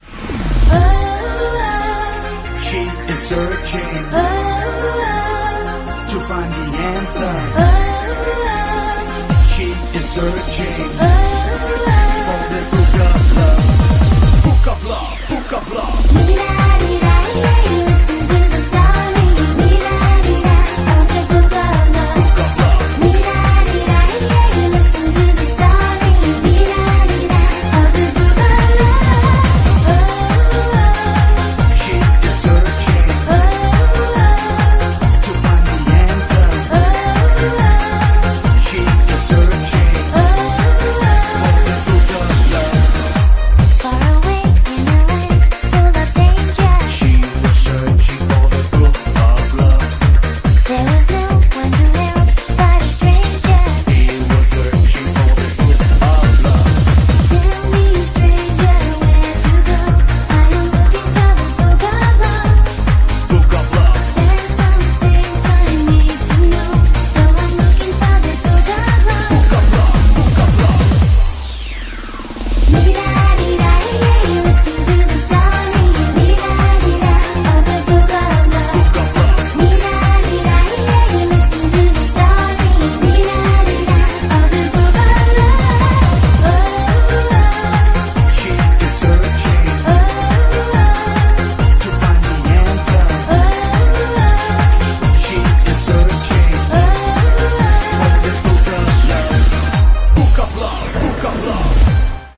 BPM140
Sorry if the song file sounds a little rough.